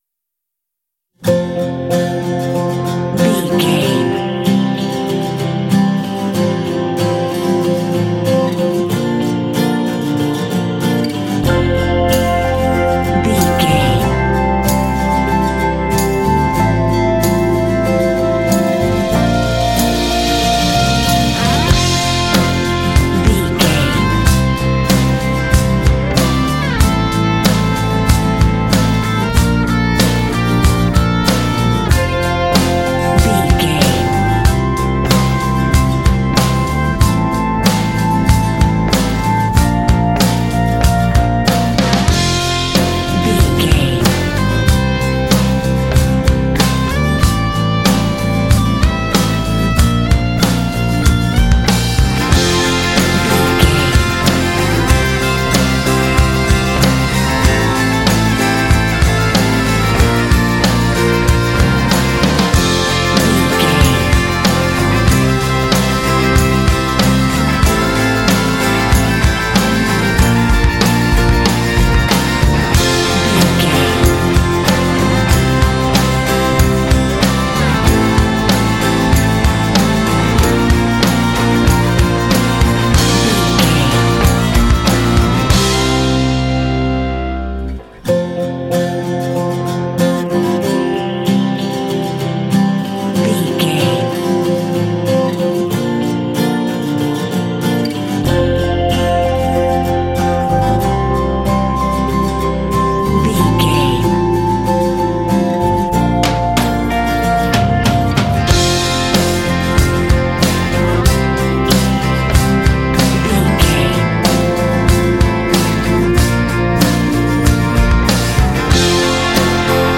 Uplifting
Ionian/Major
optimistic
hopeful
driving
sentimental
drums
electric guitar
acoustic guitar
bass guitar
synthesiser
electric organ
percussion
rock
pop
alternative rock
indie